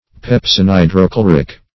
Search Result for " pepsinhydrochloric" : The Collaborative International Dictionary of English v.0.48: Pepsinhydrochloric \Pep`sin*hy`dro*chlo"ric\, a. (Physiol.
pepsinhydrochloric.mp3